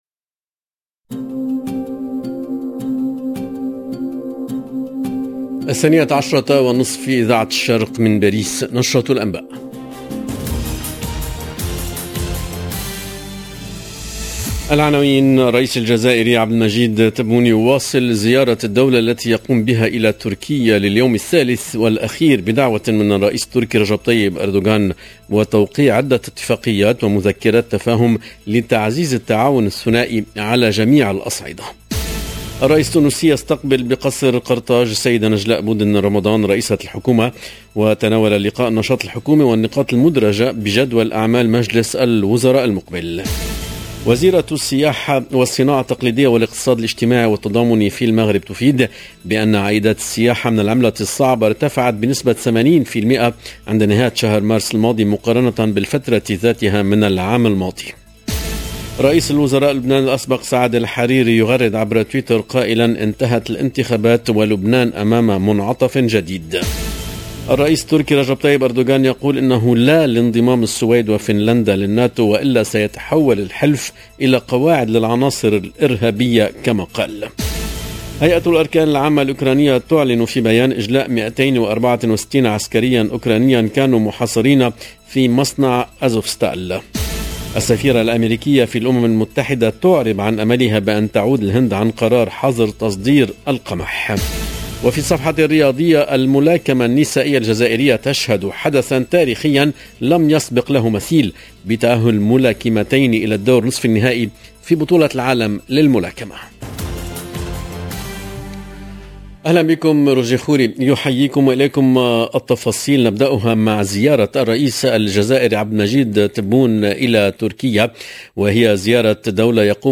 LE JOURNAL DE MIDI 30 EN LANGUE ARABE DU 17/04/22